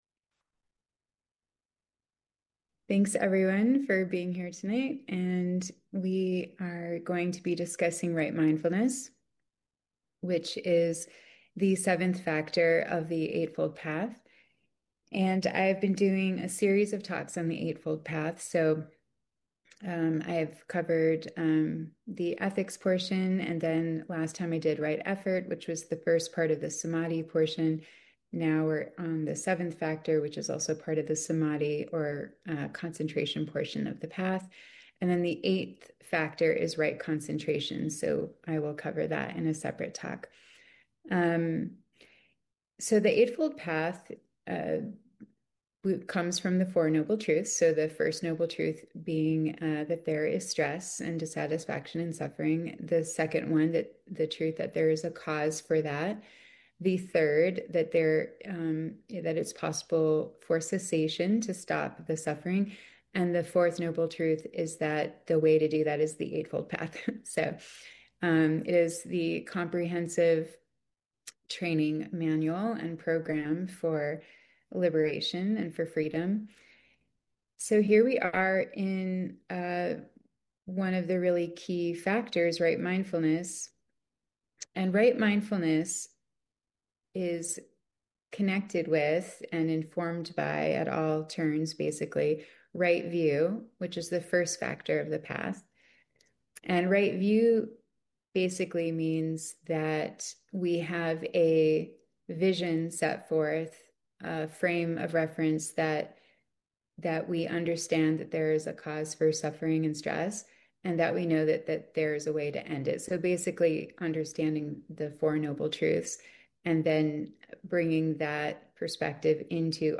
Her comments are followed up by questions and comments from those attending the meeting.